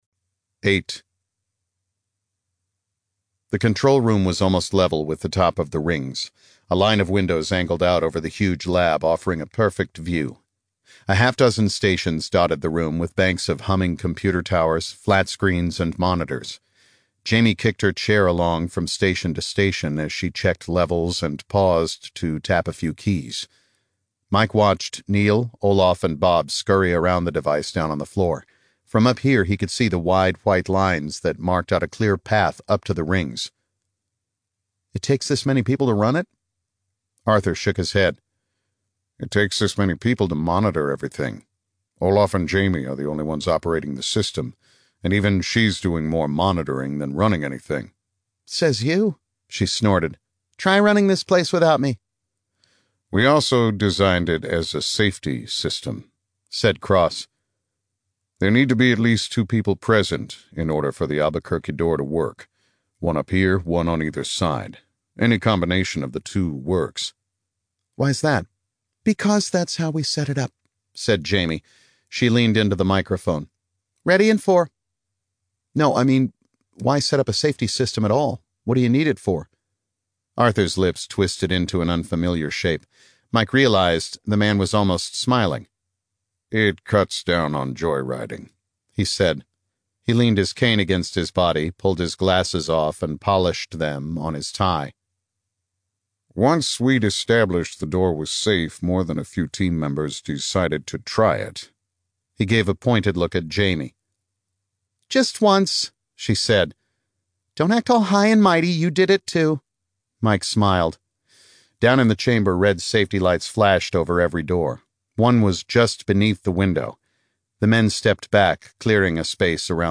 37serv Audiobooks/The Fold (Unabridged)64k